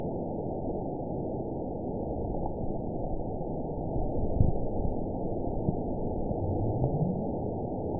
event 920446 date 03/26/24 time 01:00:01 GMT (1 year, 1 month ago) score 9.67 location TSS-AB05 detected by nrw target species NRW annotations +NRW Spectrogram: Frequency (kHz) vs. Time (s) audio not available .wav